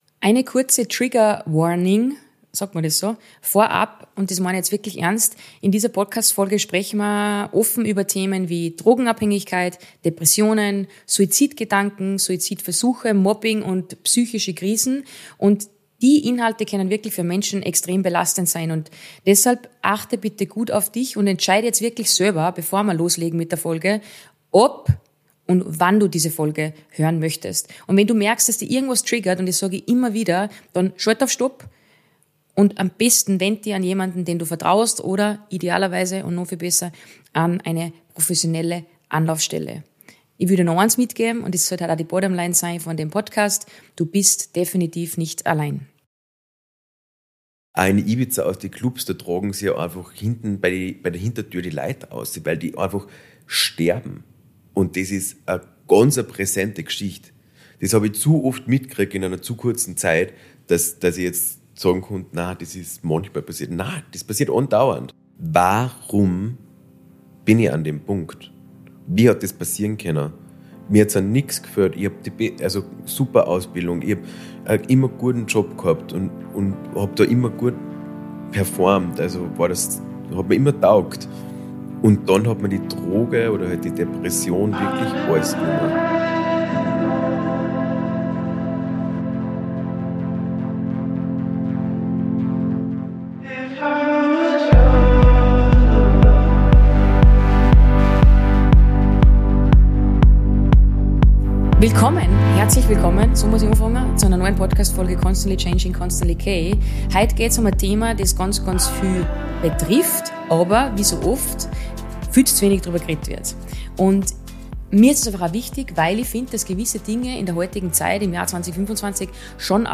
Was mich an diesem Gespräch besonders berührt hat, war seine Ehrlichkeit.